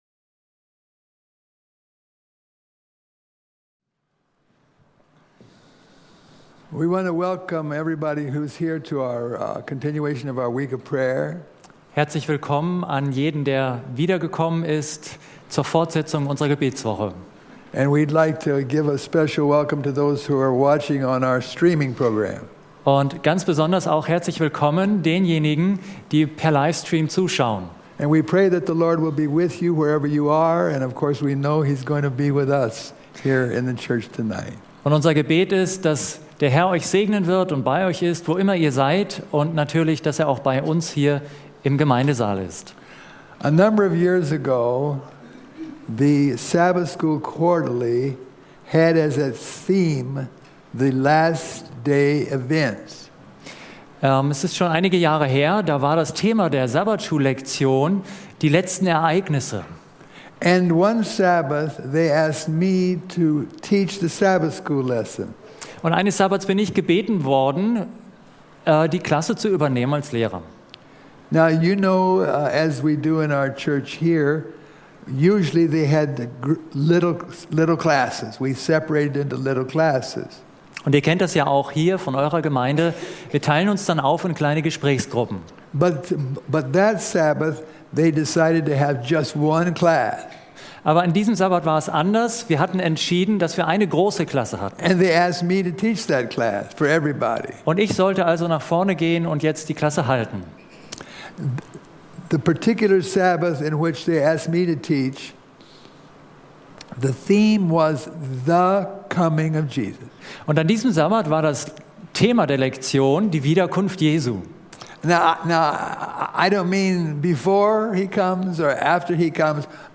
Predigt im Rahmen einer Gebetswoche: Möchten wir, dass Jesus bald wiederkommt? Oder haben wir bei genauerem Nachdenken doch noch Bedenken?